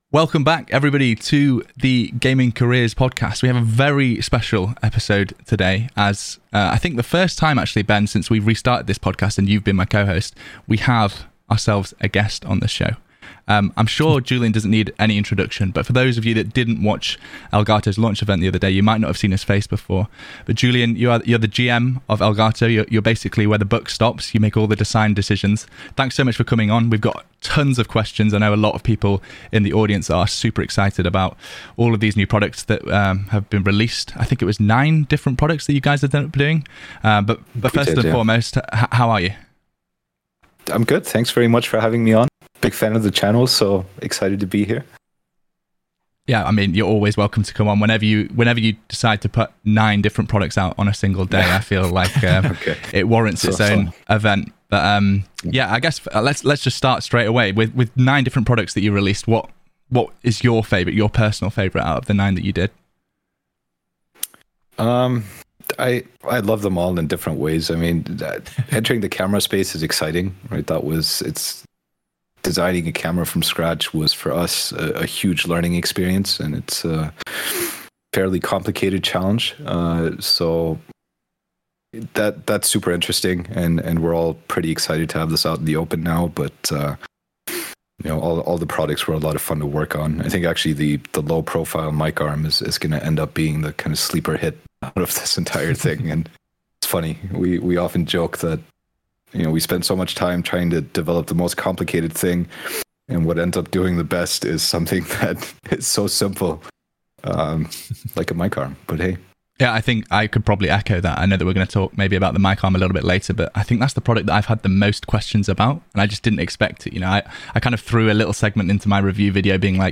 We'll be chatting live